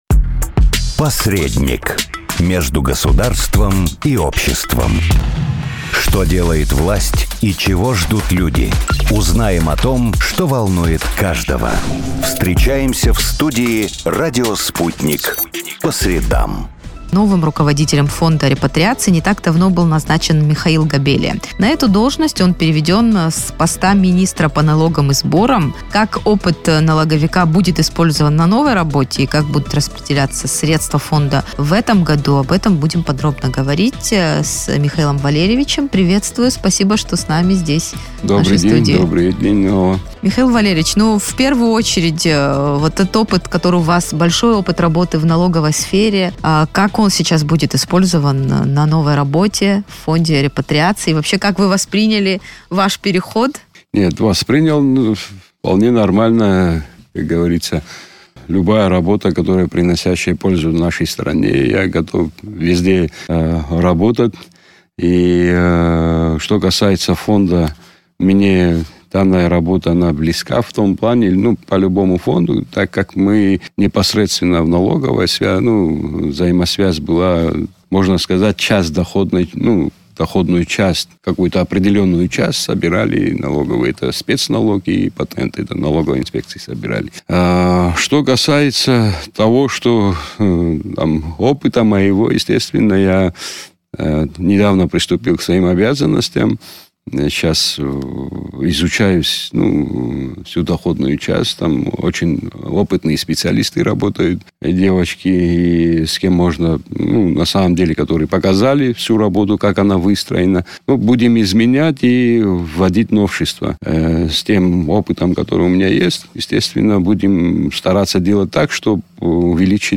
Деятельность Фонда репатриации: интервью с руководителем